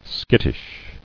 [skit·tish]